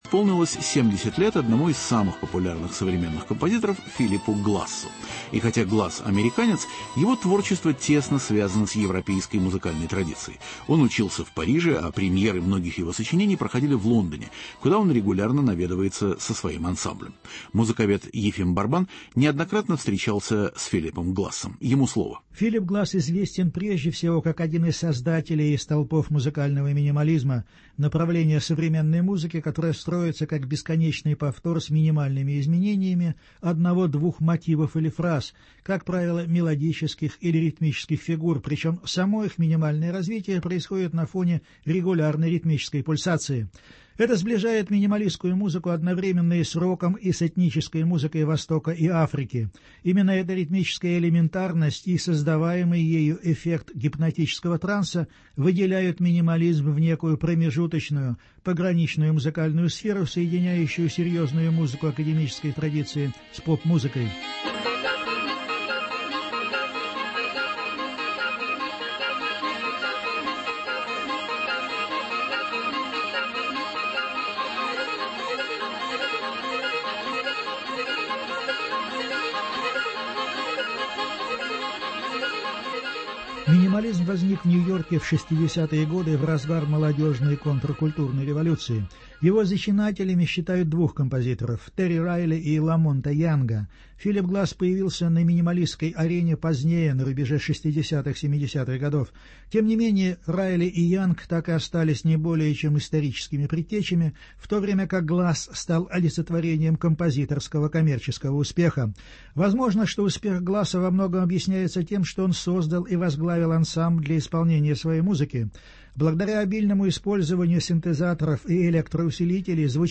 70 лет Филиппу Глассу: европейское интервью.